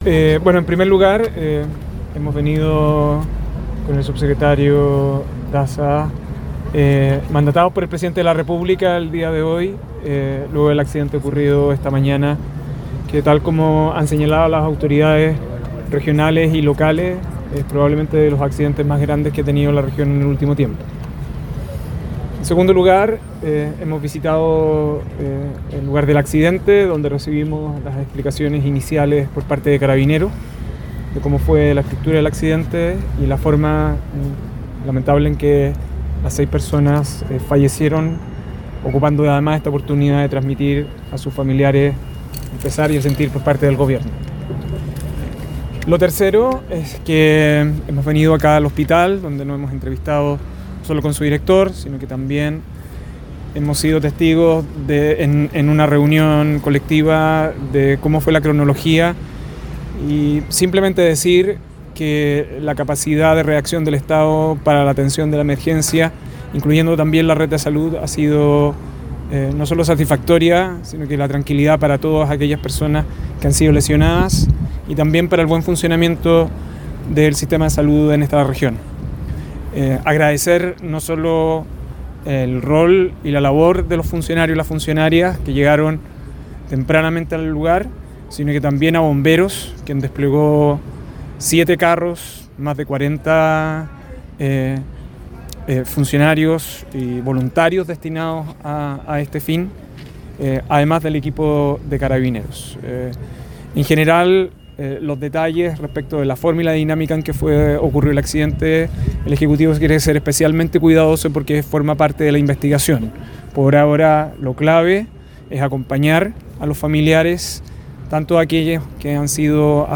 22-AUDIOS-PUNTO-DE-PRENSA.mp3